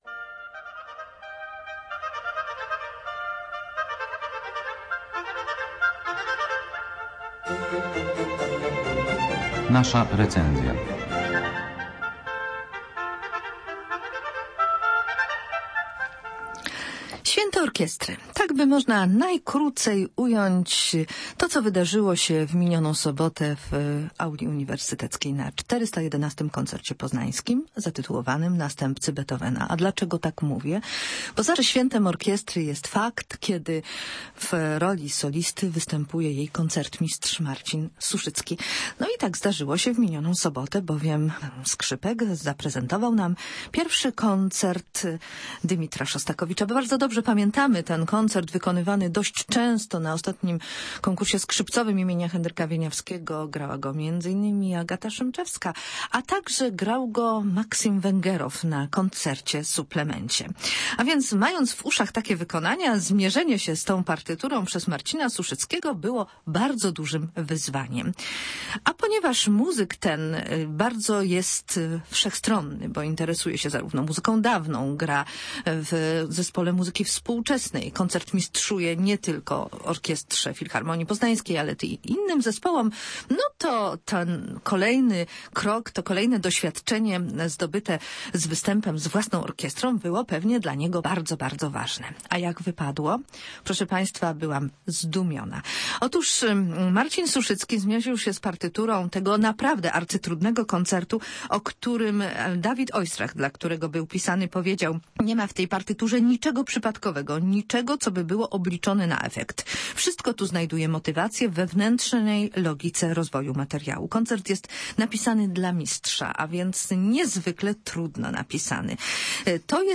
W piątek, 11 marca w Auli UAM odbył się 411 Koncert Poznański pt. "Następcy Beethovena".